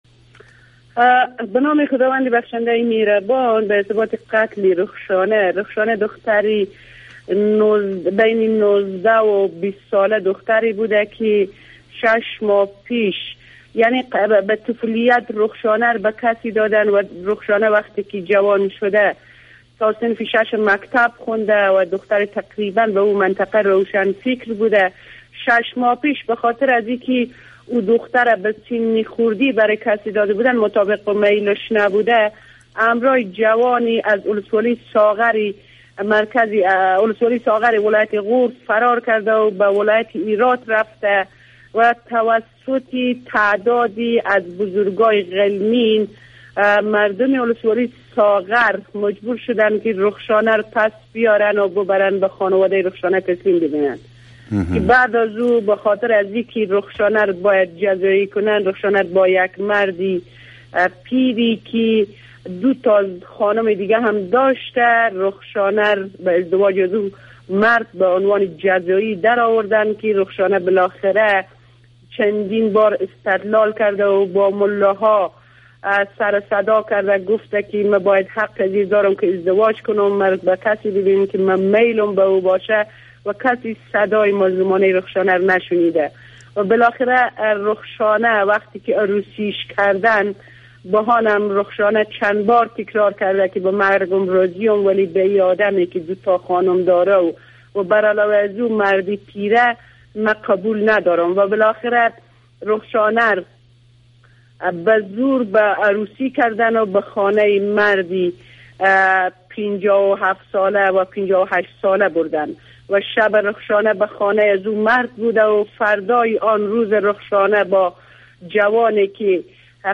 Interview with Ghor governor2